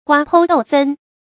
瓜剖豆分 guā pōu dòu fēn 成语解释 象瓜被剖开，豆从荚里裂出一样。比喻国土被人分割。